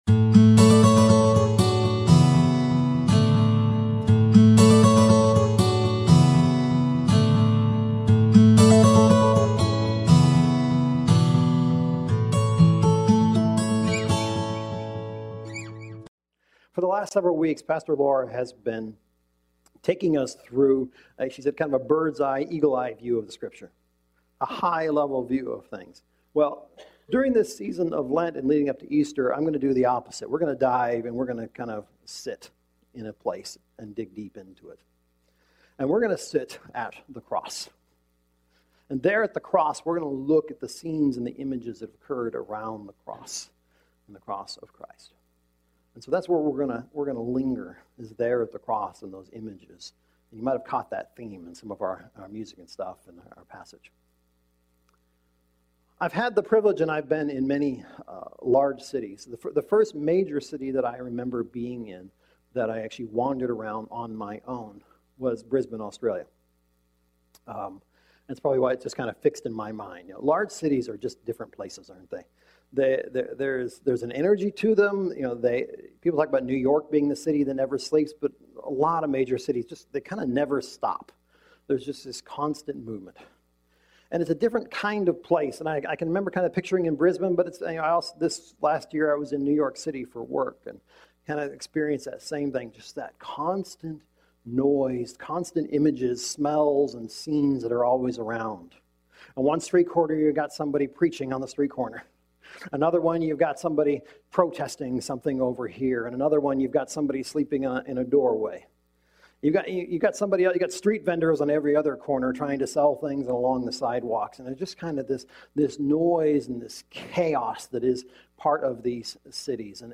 Sermons | Hope Wesleyan Church